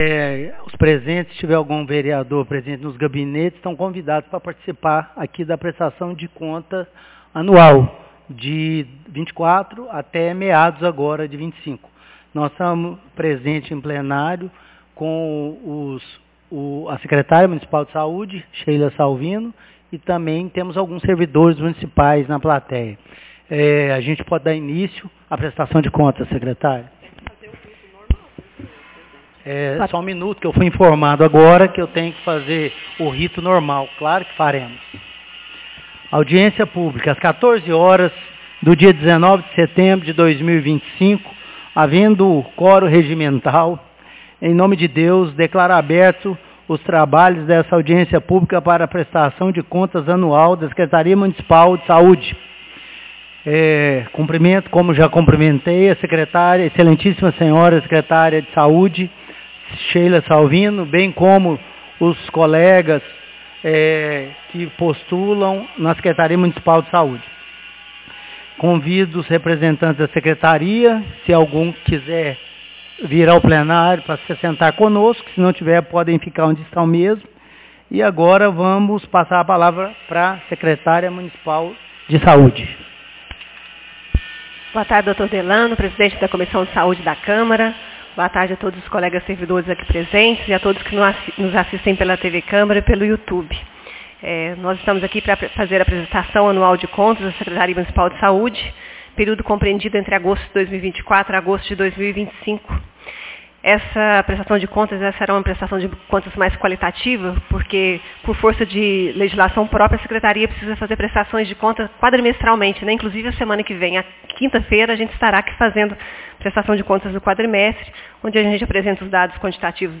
Audiencia Publica Prestação de contas da Sec Mun de Saude 19 de setembro de 2025